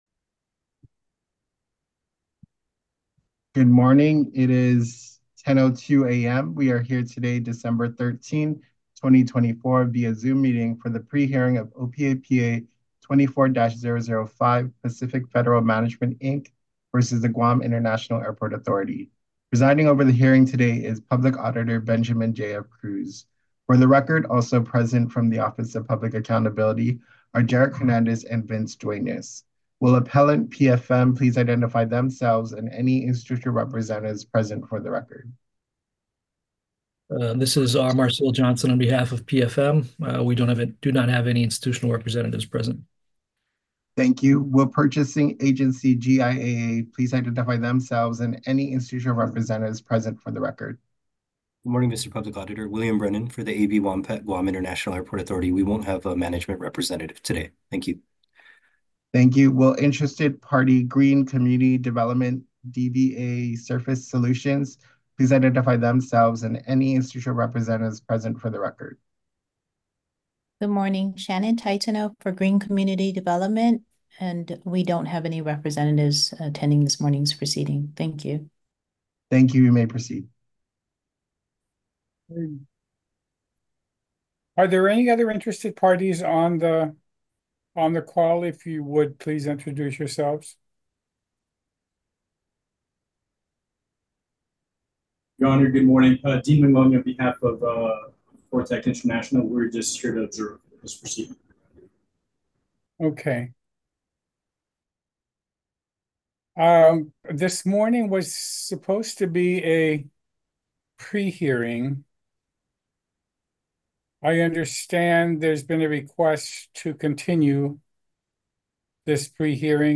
Pre-Hearing Conference - December 13, 2024